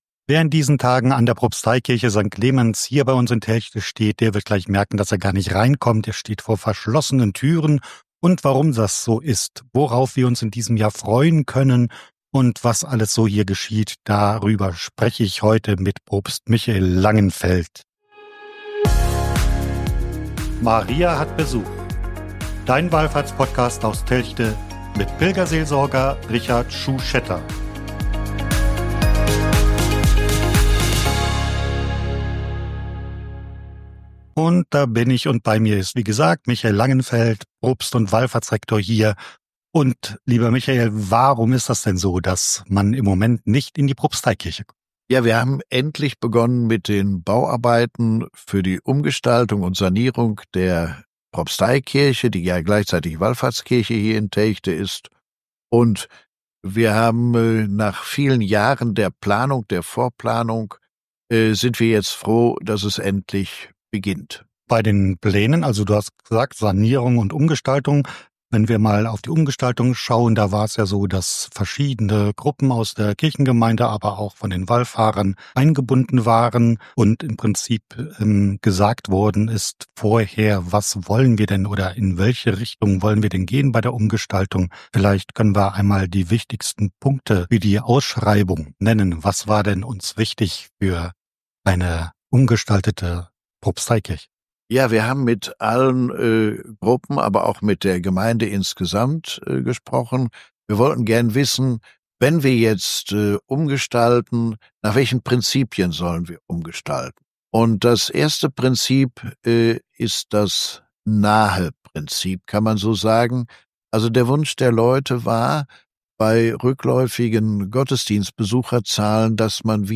Wir bitten um Entschuldigung für den furchtbaren Ton bei der vorangegangenen Version und präsentieren eine überarbeitete Version.